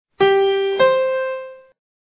When there is no musical context more complex, the key is determined by the perfect cadence, in which the tonic (or keytone) follows the dominant (that is, the tonic's fifth).
Perfect cadence